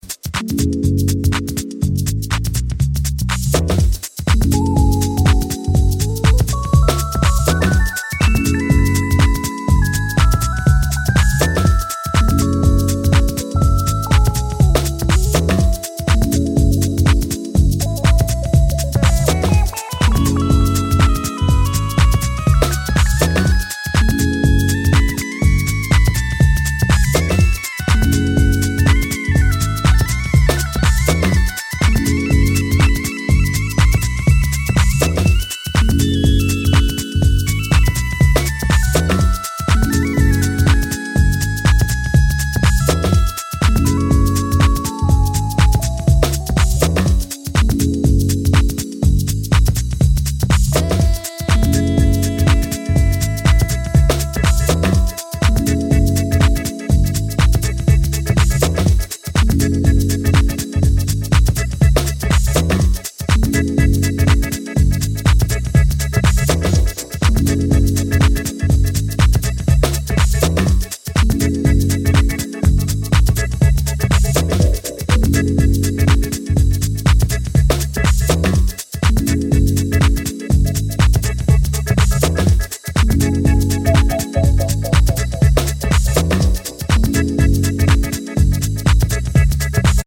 deep house
soulful vocals, jazzy harmonies, funky bass lines